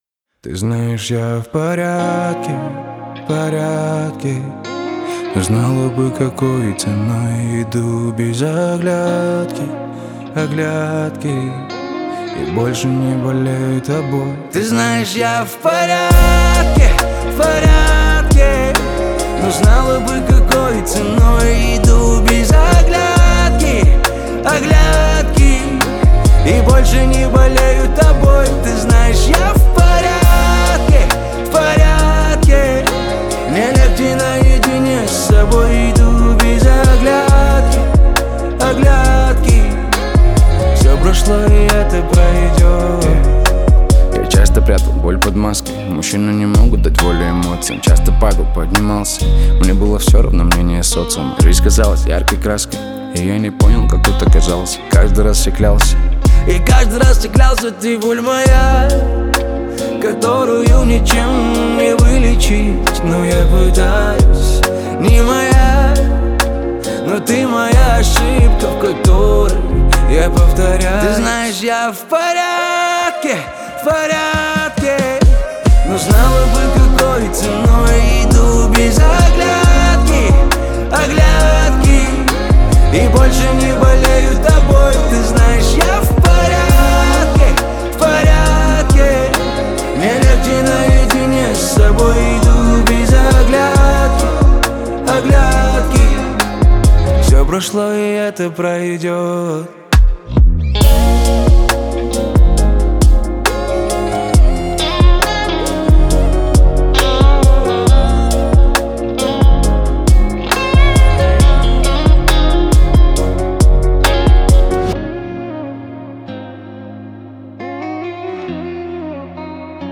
• Жанр: Русские